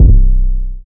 MURDA_808_BLACKBALL_C.wav